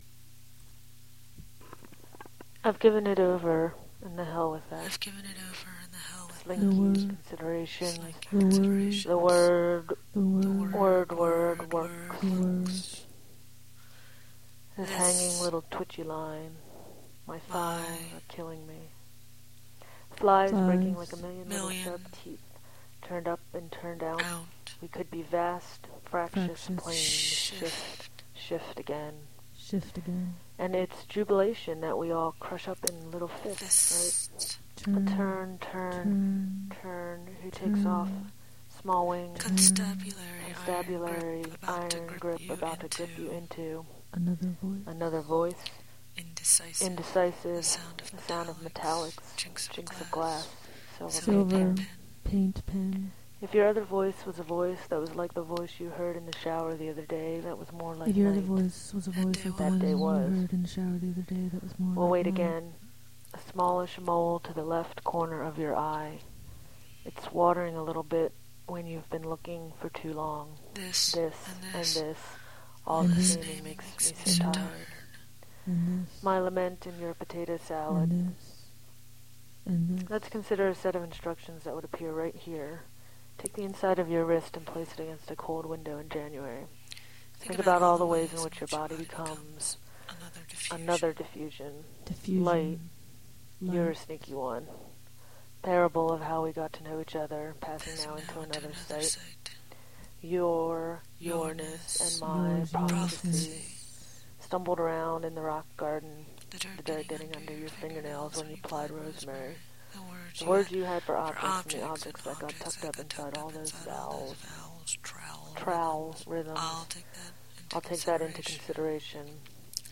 Audio text composition, 2:18, 2010.